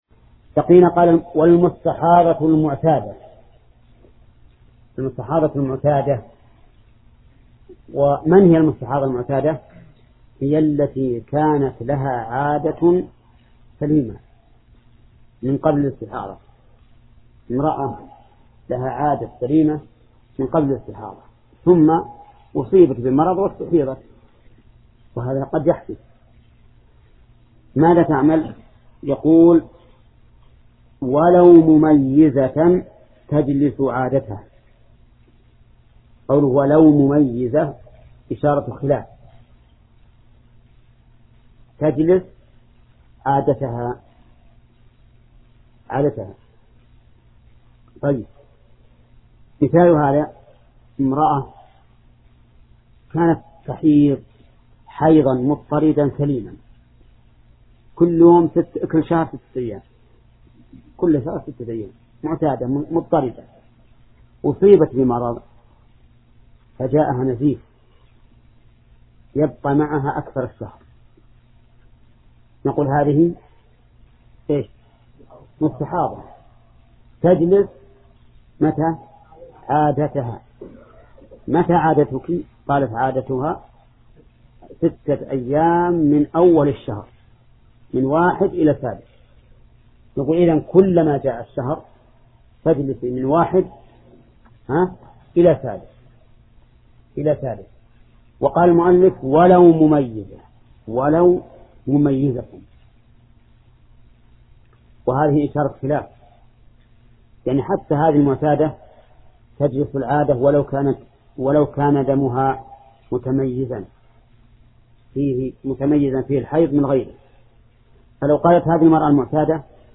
درس (25): باب الحيض